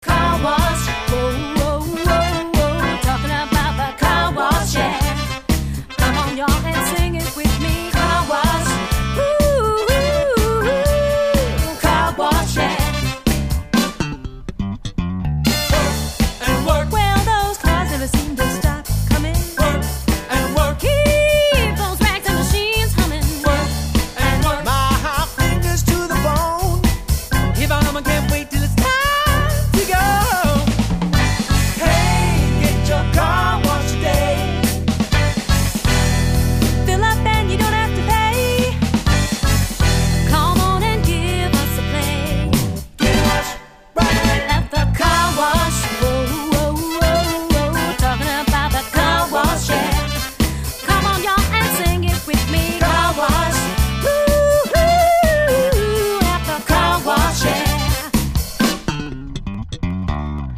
Disco & Funk